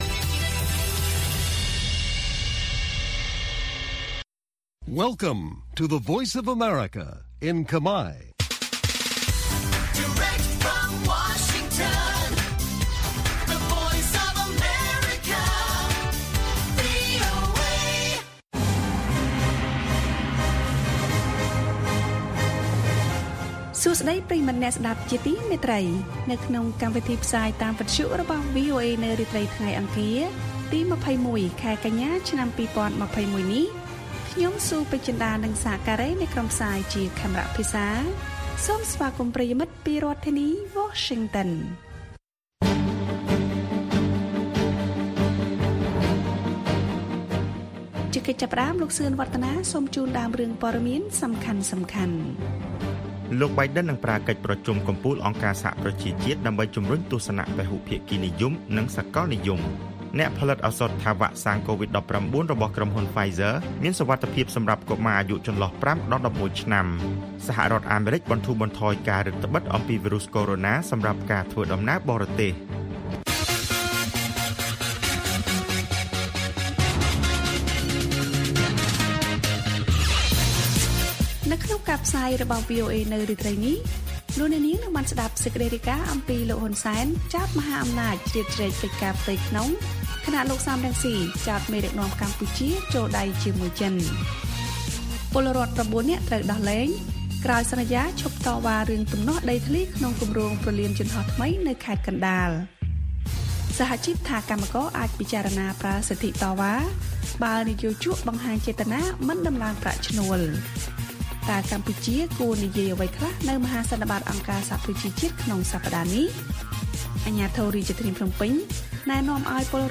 ព័ត៌មានពេលរាត្រី៖ ២១ កញ្ញា ២០២១